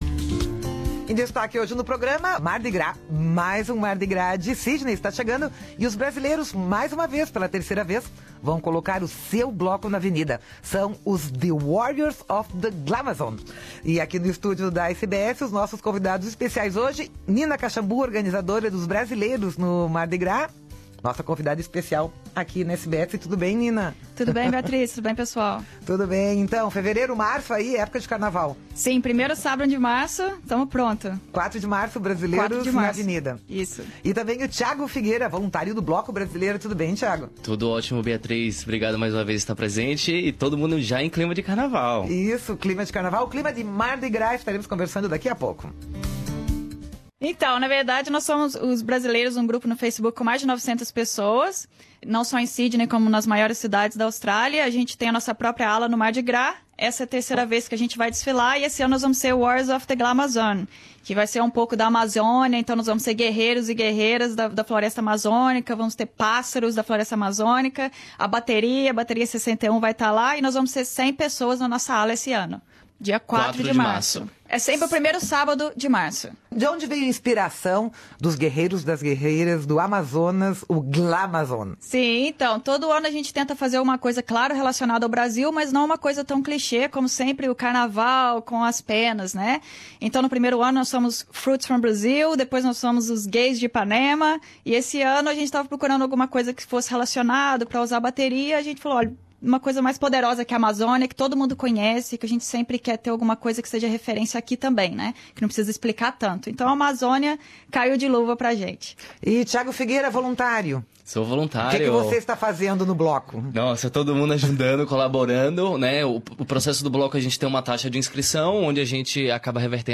Nesta entrevista, eles também falam do quanto é importante abrir para a família sobre a sua sexualidade, e ser aceito por ela.